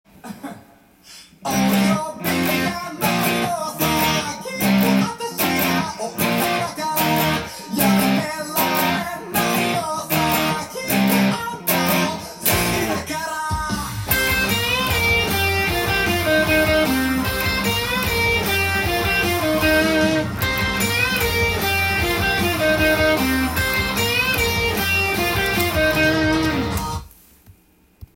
音源に合わせて譜面通り弾いてみました
リズムも８分音符と４分音符だらけですので
ギターメロディーにハーフチョーキングが出てきますので